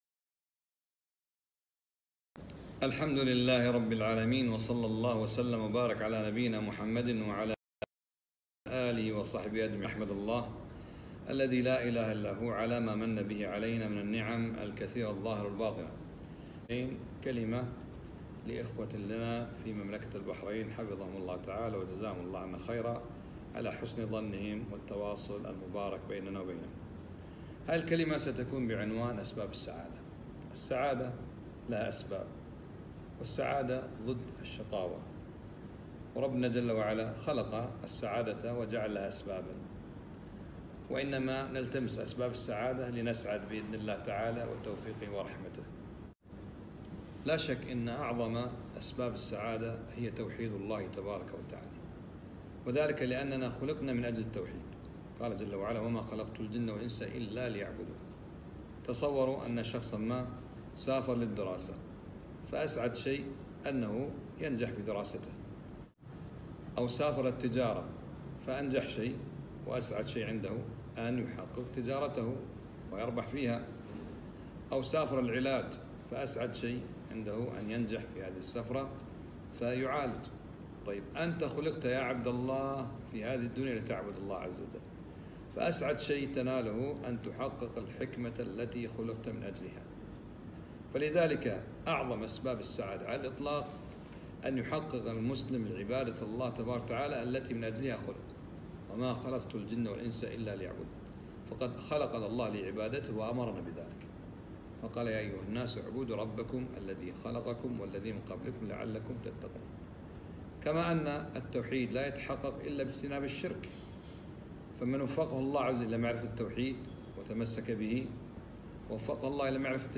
كلمة - أسباب السعادة